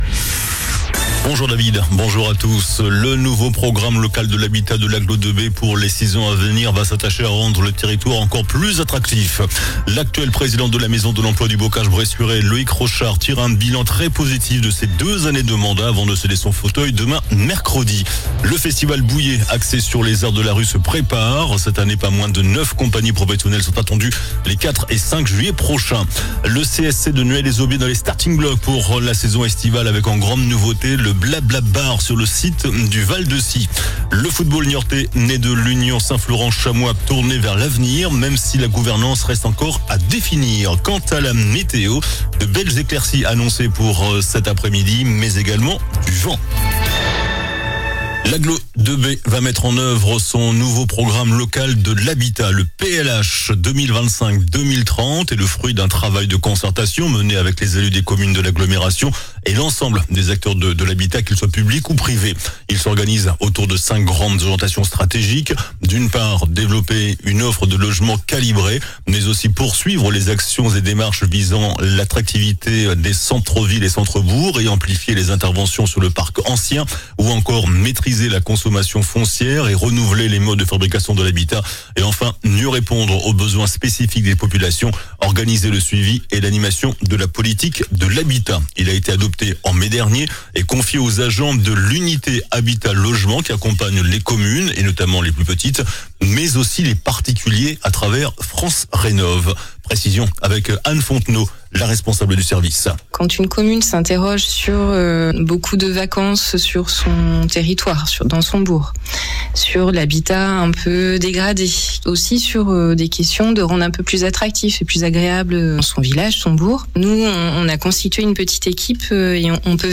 JOURNAL DU MARDI 03 JUIN ( MIDI )